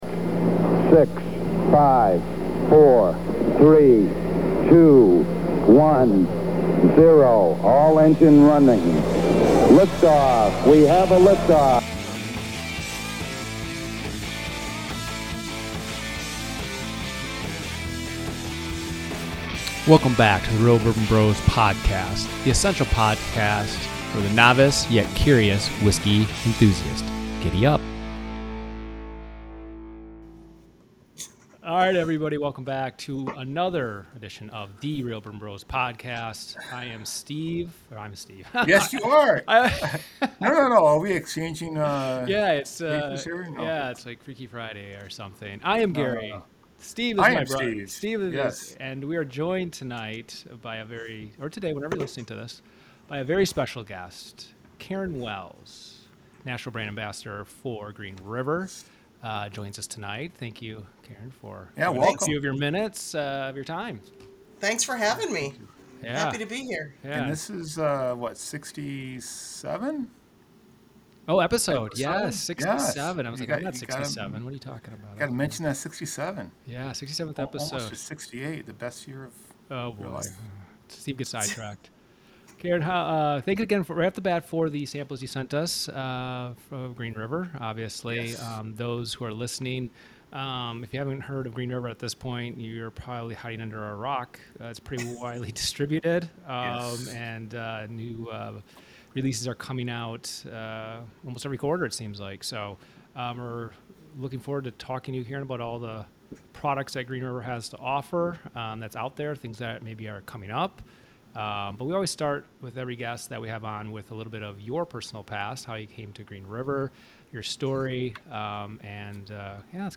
The essential podcast for the novice, yet curious whiskey enthusiast!We are two blood brothers chit chatting about one our common loves in life, whiskey!